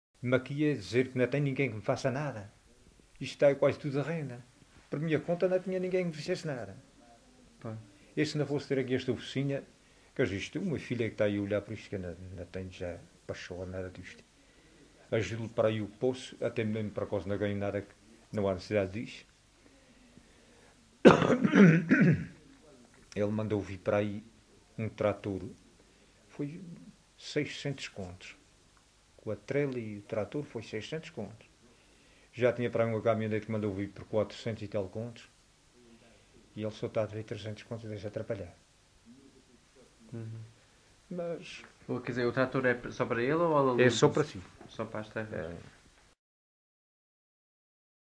LocalidadeCovas (Santa Cruz da Graciosa, Angra do Heroísmo)